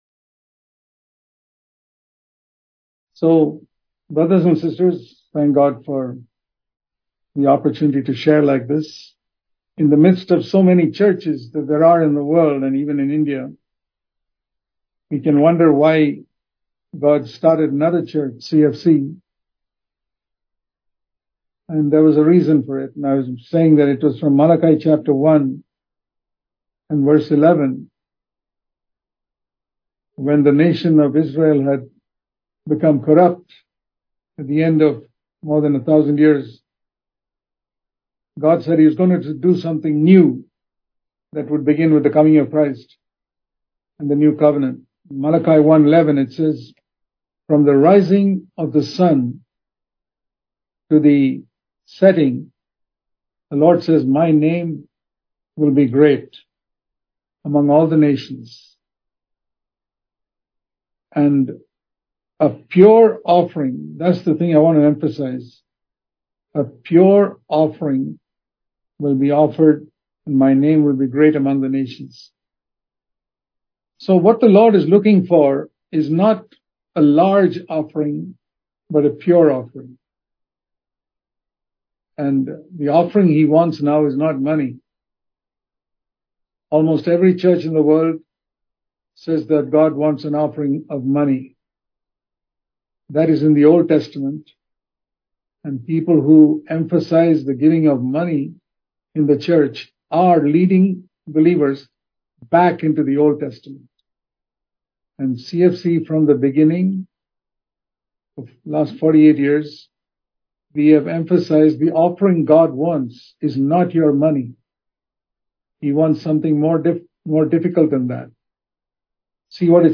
Purifying the Church Click here to View All Sermons Recent Sermons Bro.